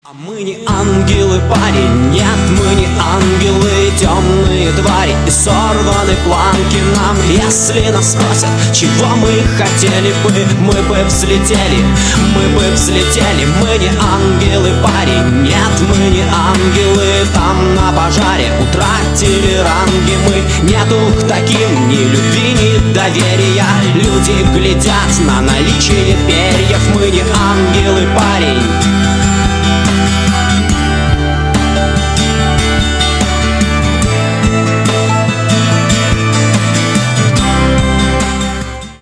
гитара , душевные
русский рок